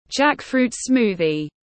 Sinh tố mít tiếng anh gọi là jackfruit smoothie, phiên âm tiếng anh đọc là /ˈdʒæk.fruːt ˈsmuː.ði/
Jackfruit smoothie /ˈdʒæk.fruːt ˈsmuː.ði/